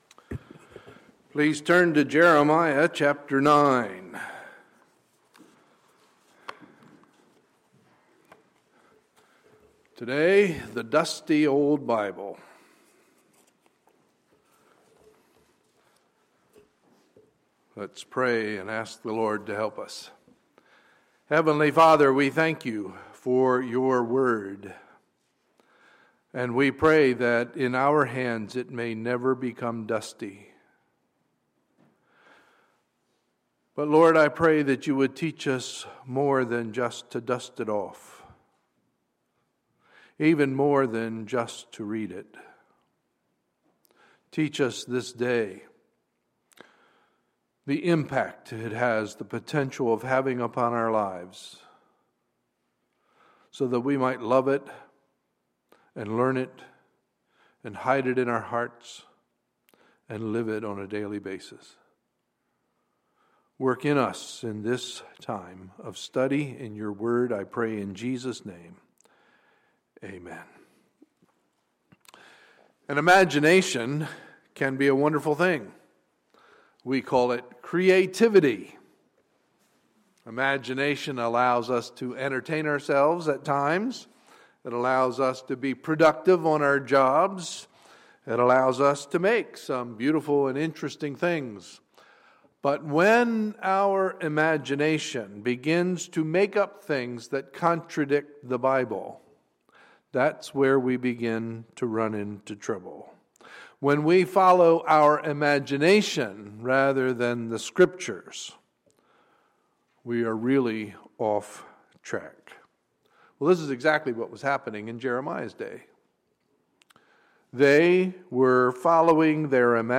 Sunday, March 15, 2015 – Sunday Morning Service